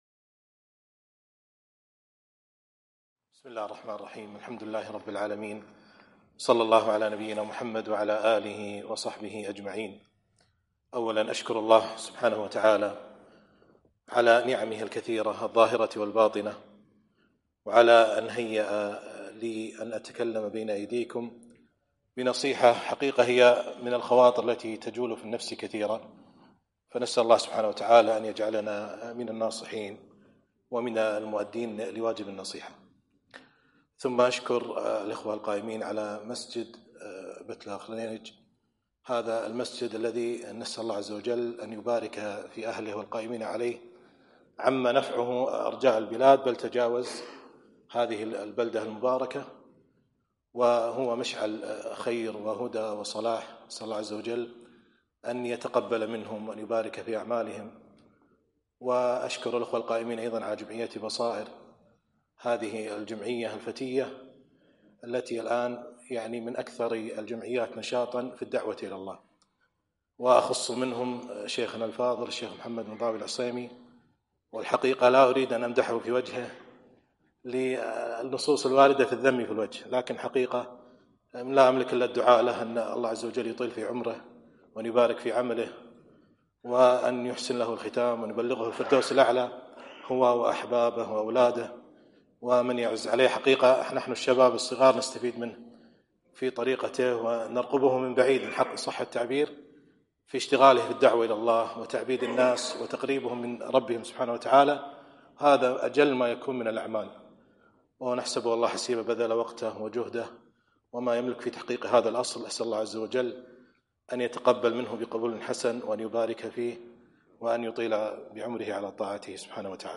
محاضرة بعنوان حب وحزم وحوافز مع الأبناء